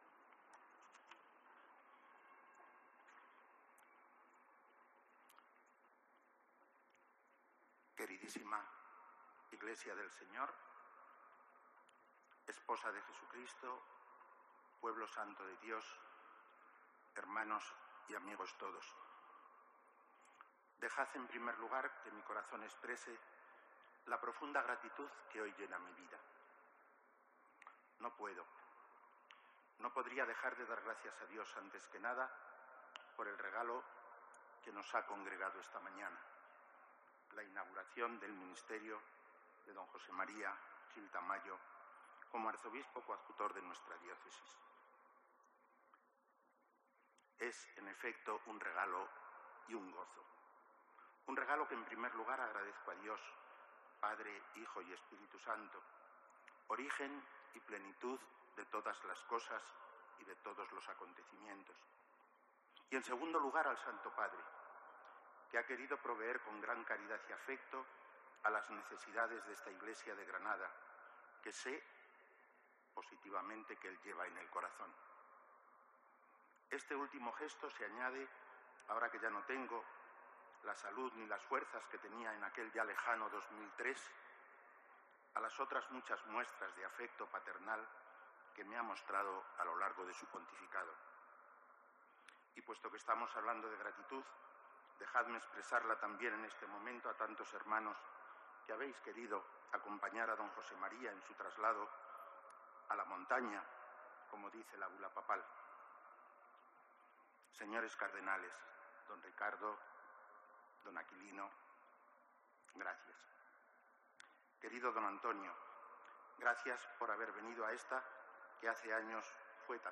Homilía pronunciada por el Arzobispo de Granada monseñor Javier Martínez
En la toma de posesión de su ministerio como Arzobispo Coadjutor de Granada de Don José María Gil Tamayo